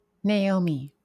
/nˈmi/
(US)